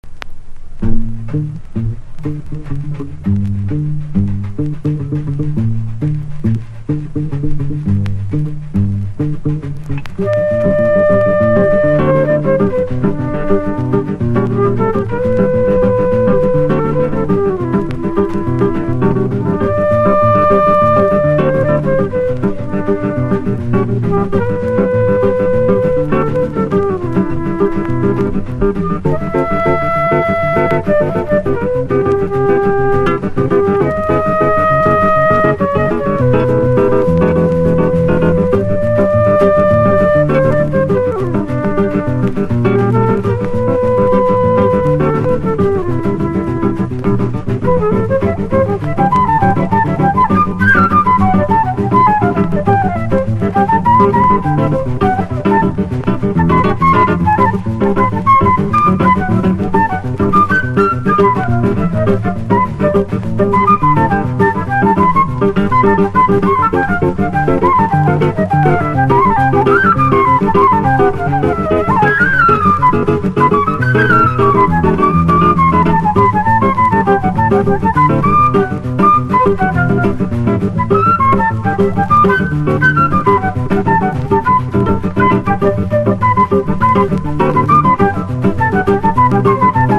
ジャズ･フルート奏者